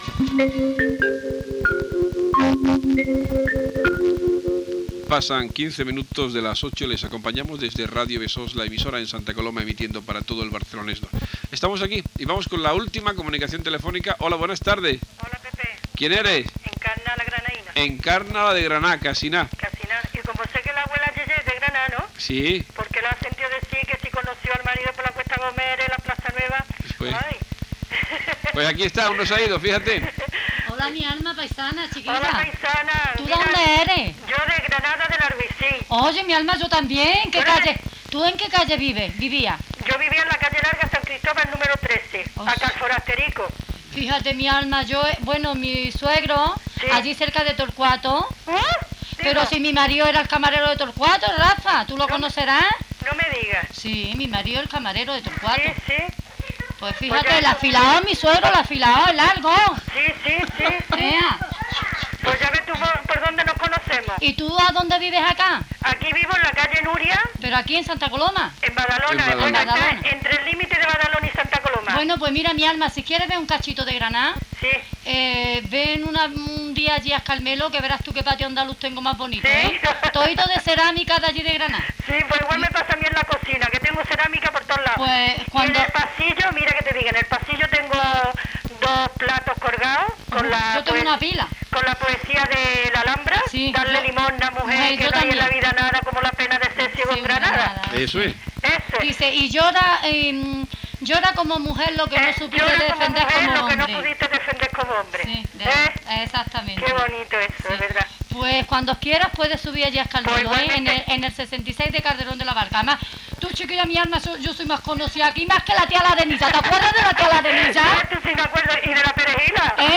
Identificació i diàleg de dues oïdores de Granada.
FM